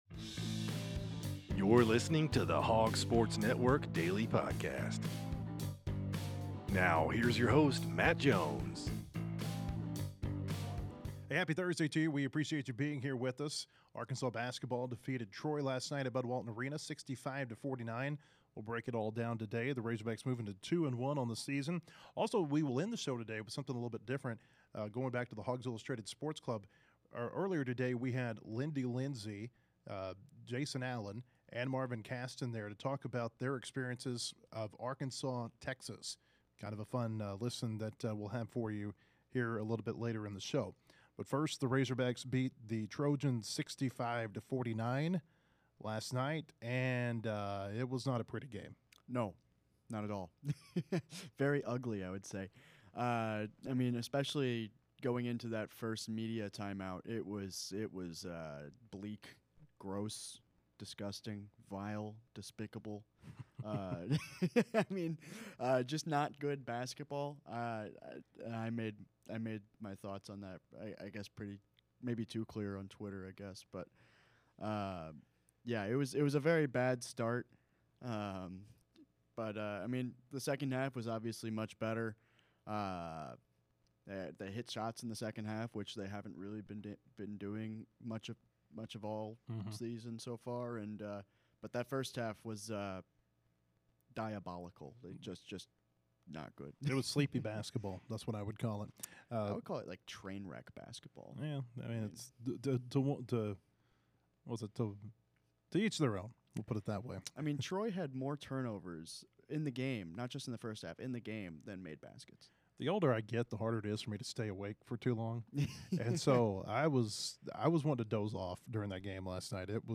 Arkansas basketball game review and Texas vs Arkansas panel discussion.